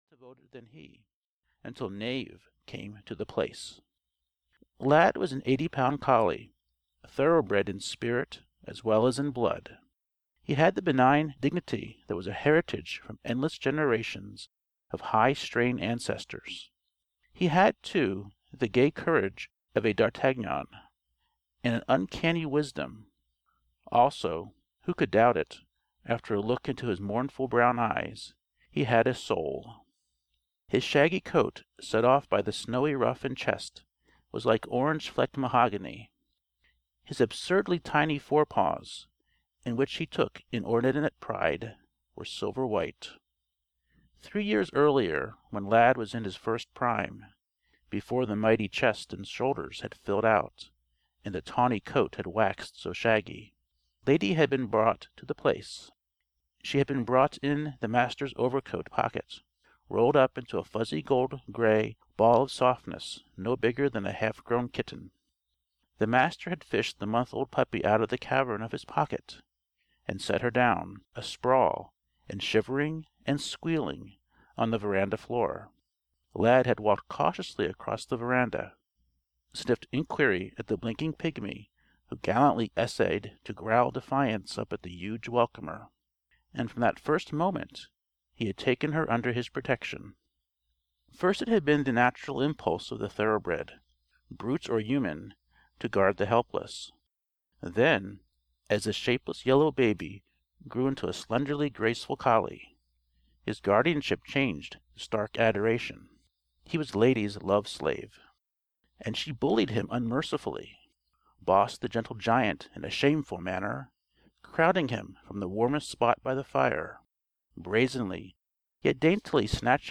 Lad: A Dog (EN) audiokniha
Ukázka z knihy